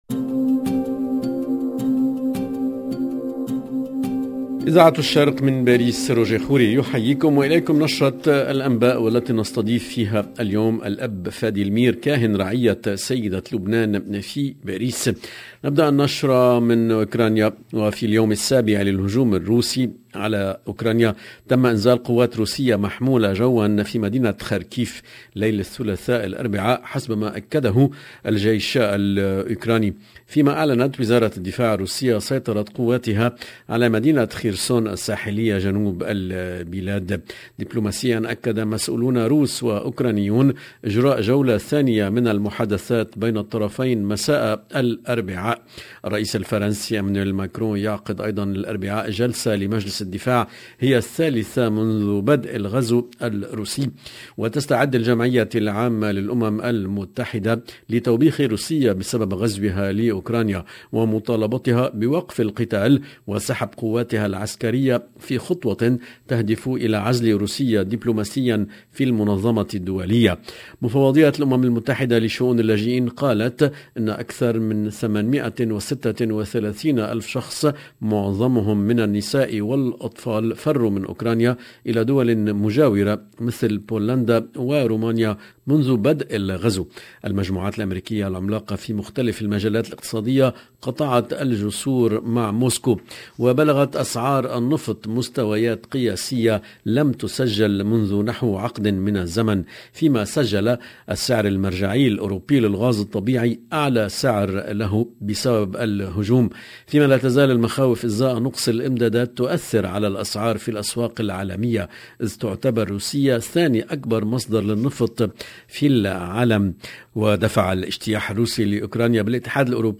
LE JOURNAL DU SOIR EN LANGUE ARABE DU 2/02/22